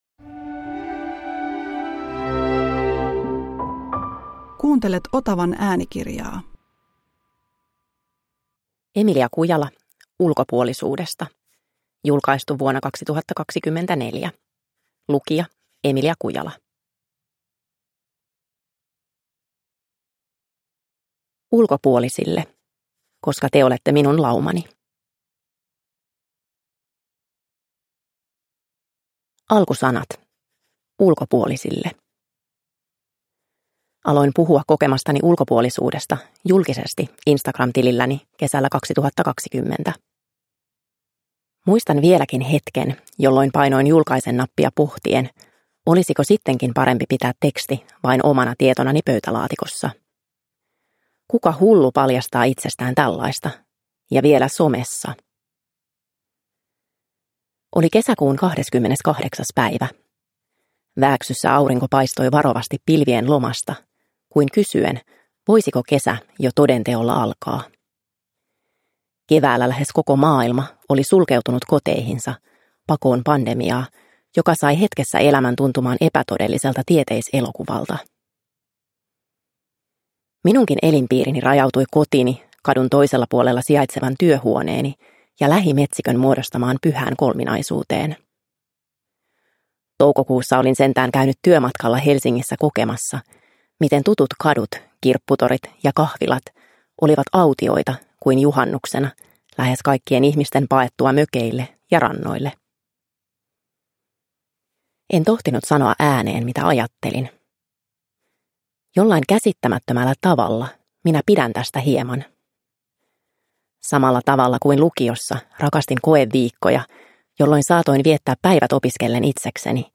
Ulkopuolisuudesta – Ljudbok